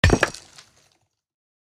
axe-mining-stone-7.ogg